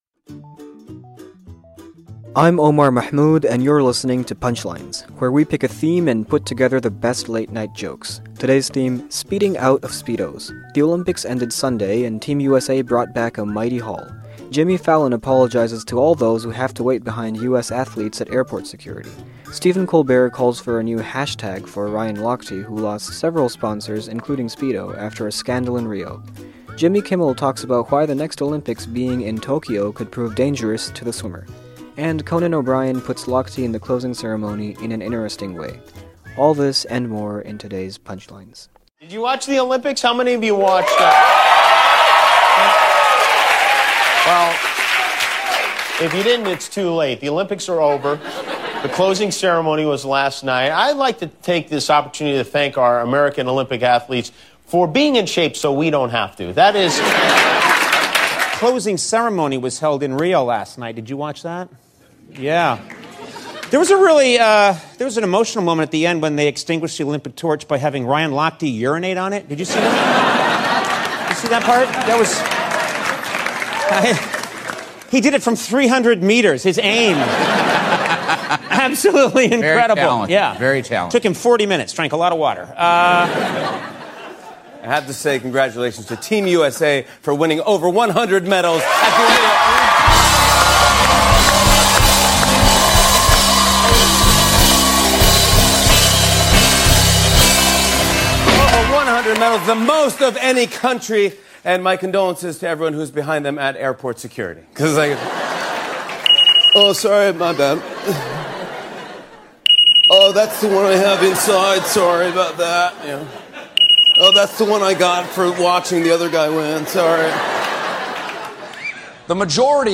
The late-night comics on the end of the Olympics and the beginning of swimmer Ryan Lochte's scandal.